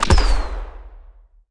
Download Sfx Block Regular Explode sound effect for video, games and apps.
Sfx Block Regular Explode Sound Effect
sfx-block-regular-explode.mp3